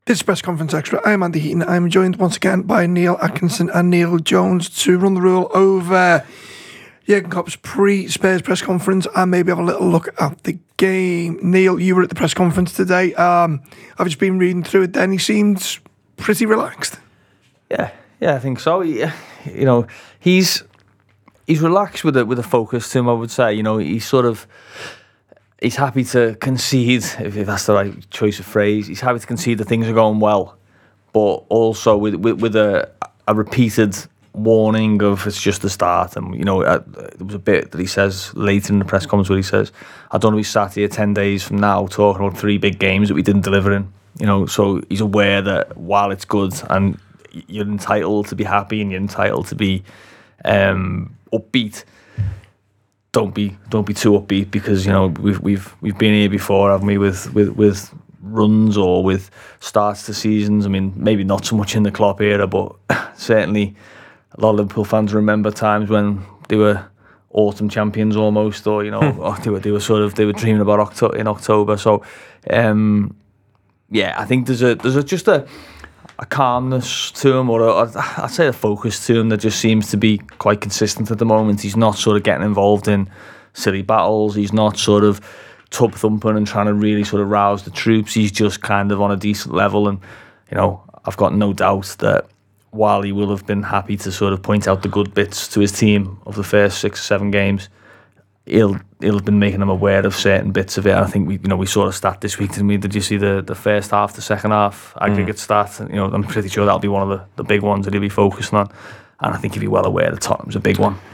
Below is a clip from the show – subscribe for more on the Tottenham v Liverpool press conference…